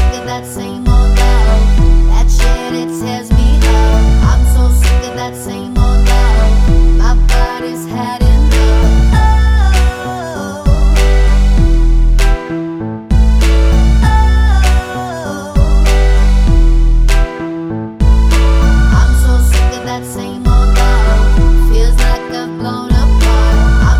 clean Pop (2010s) 3:49 Buy £1.50